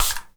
spray_bottle_11.wav